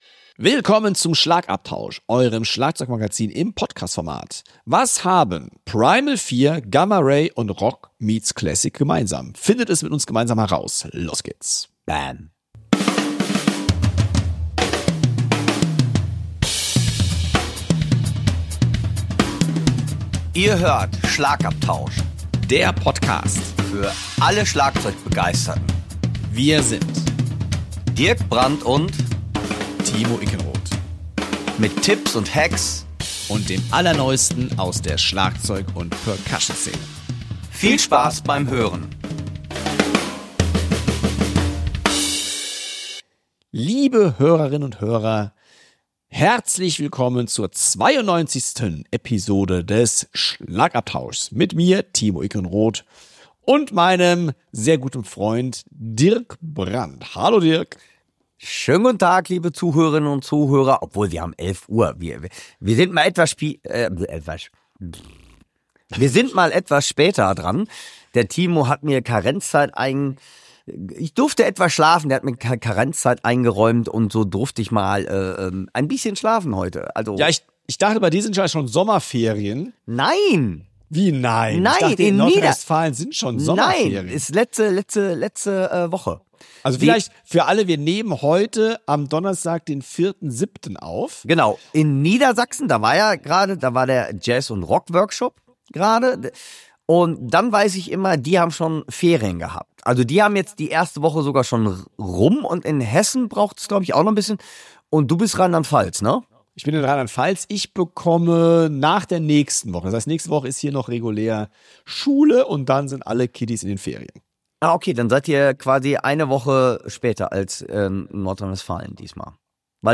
Die Hosts starten locker mit etwas Small Talk in die 92. Folge des Schlagabtausch.